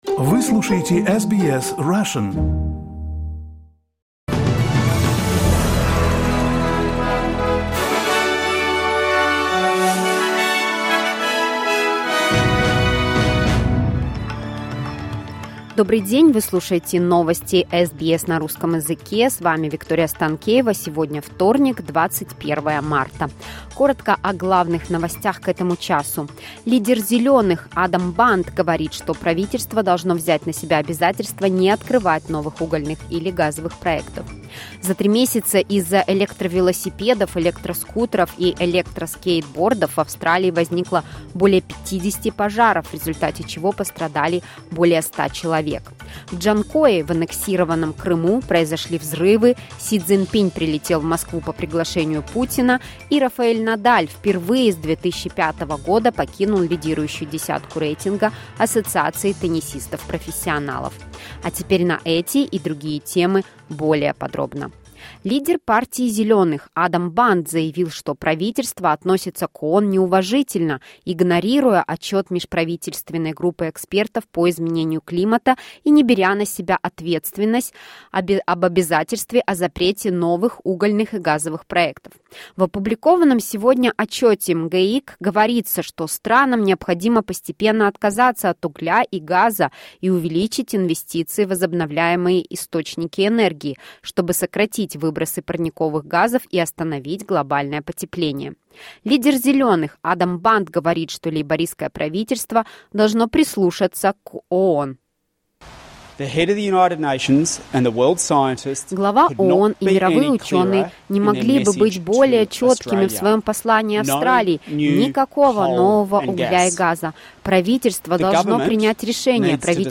SBS news in Russian — 21.03.2023